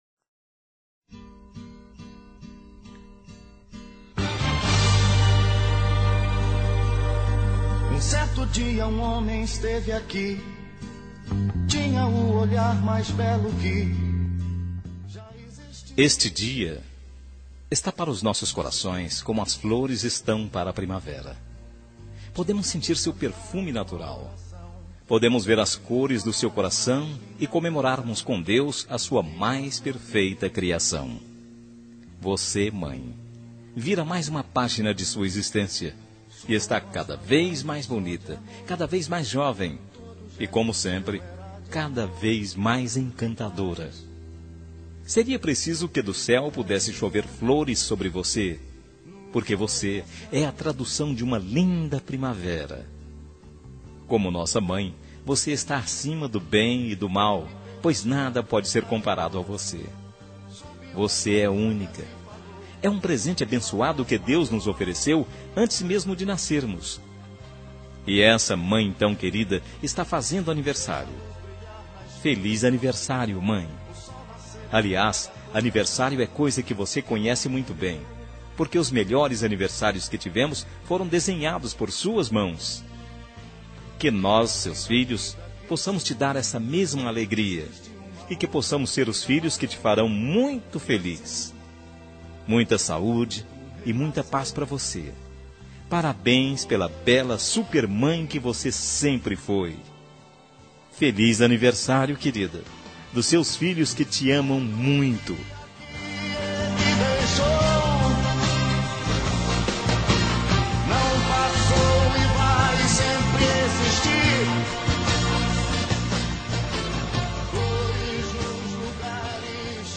Telemensagem de Aniversário de Mãe – Voz Masculina – Cód: 1446 – Plural